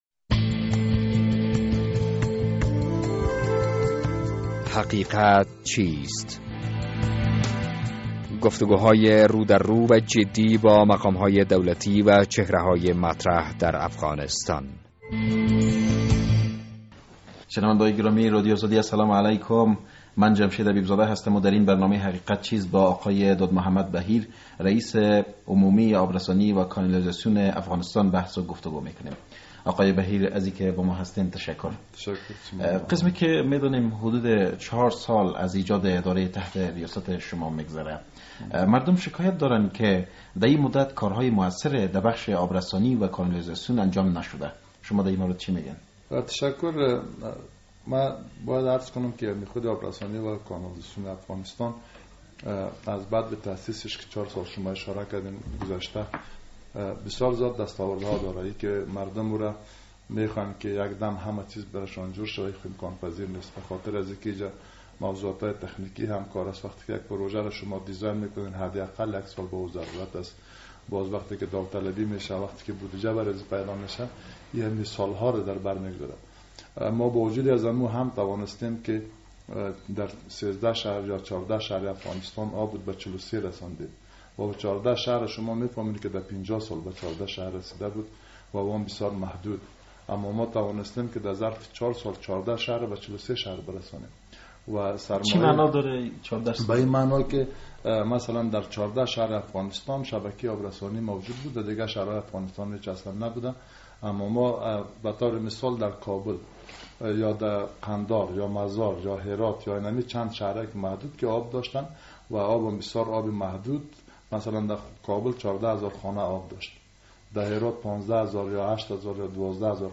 در این برنامه حقیقت چیست با داد محمد بهیر رییس عمومی آب رسانی و کانالیزاسیون افغانستان، گفتگو کرده ایم.